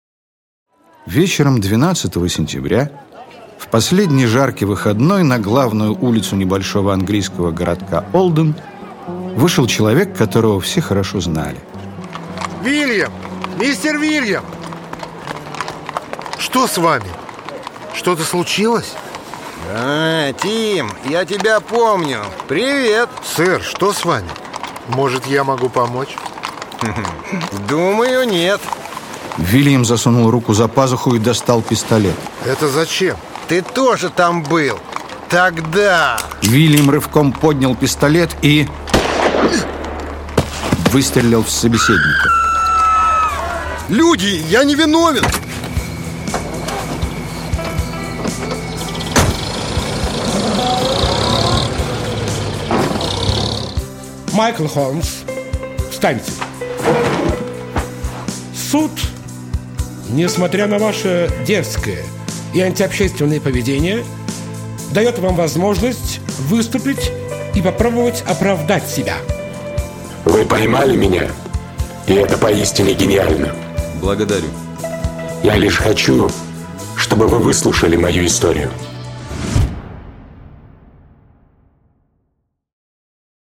Аудиокнига Часовщик. 7-я серия. Рассказ «Безликий» | Библиотека аудиокниг